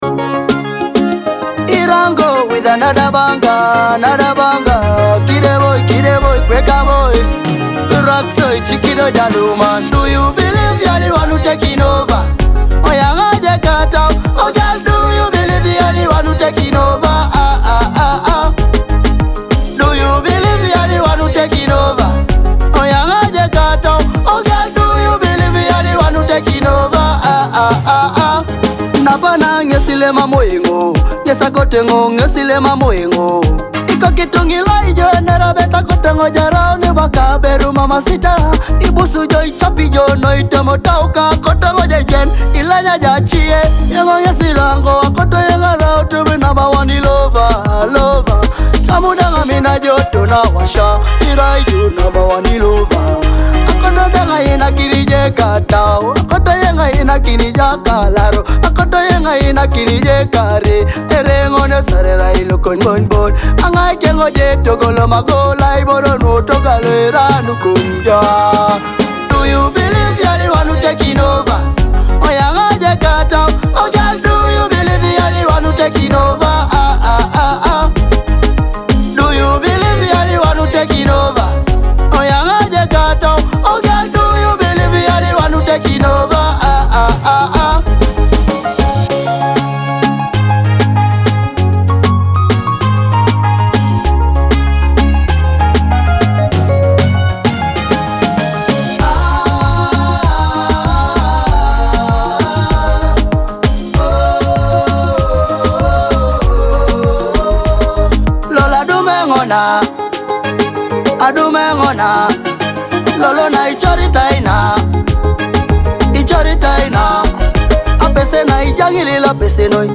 blends Afrobeat and dancehall with a true Teso vibe